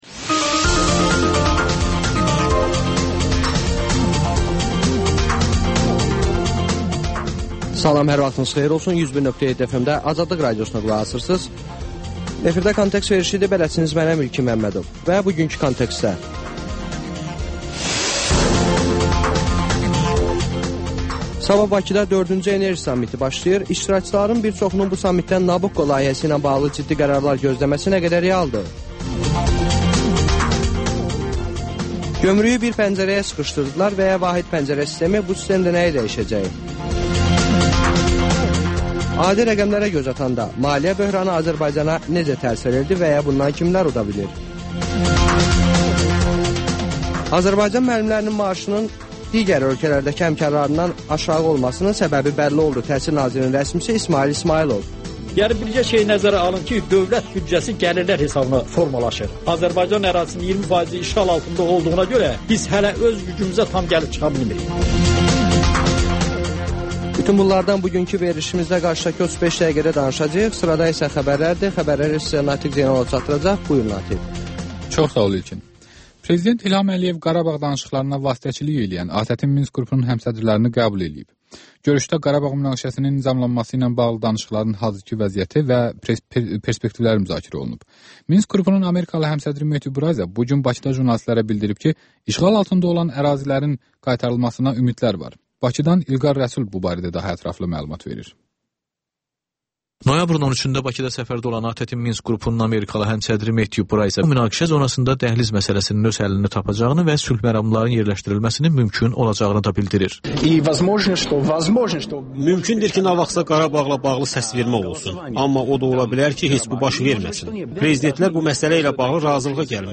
Xəbərlər, müsahibələr, hadisələrin müzakirəsi, təhlillər, sonda TANINMIŞLAR: Ölkənin tanınmış simaları ilə söhbət